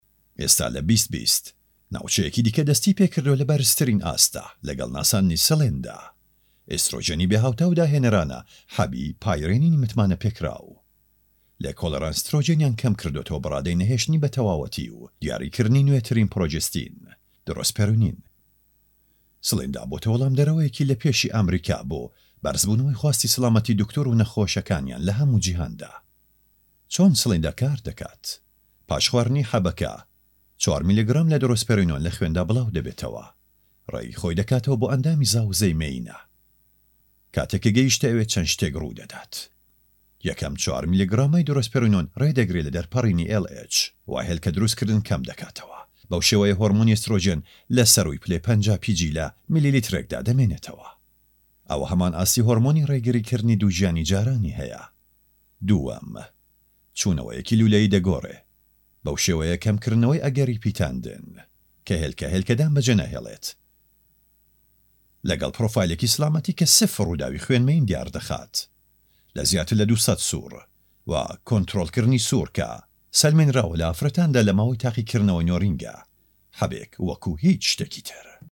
Male
Adult
Documentary
informative